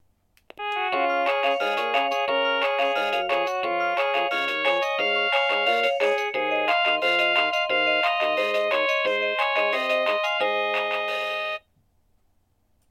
• 36 vyzváněcí tónů k výběru, ukázky zvonění: